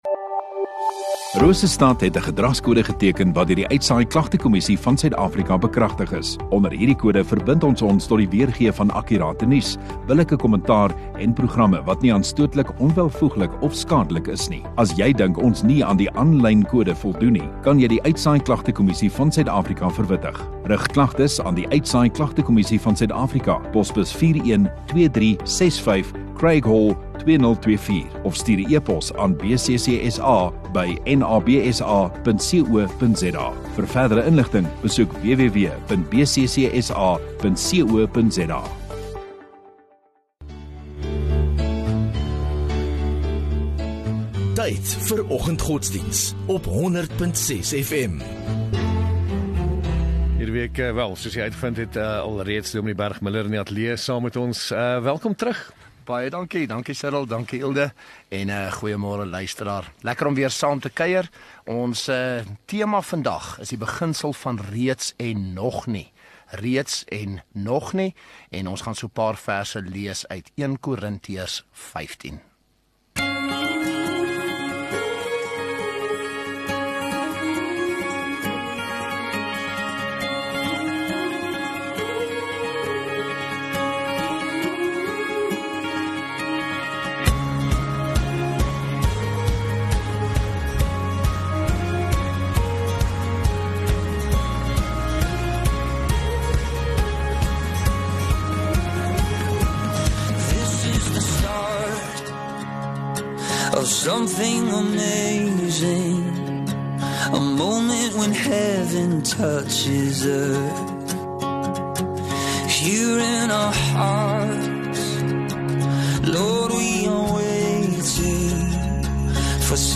5 Jun Woensdag Oggenddiens